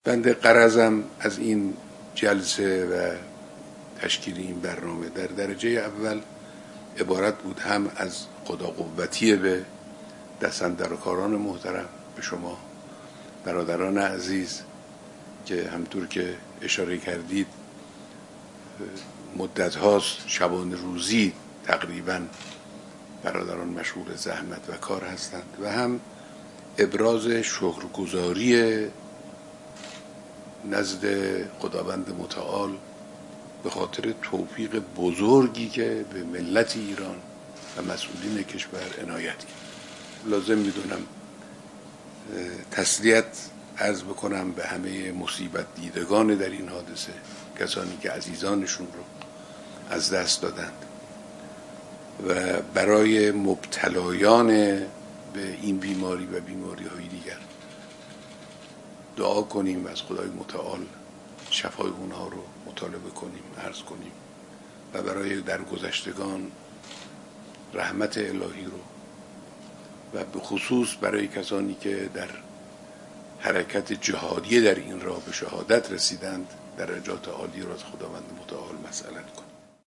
بخشی از بیانات رهبر انقلاب در ارتباط تصویری با ستاد ملی مقابله با کرونا.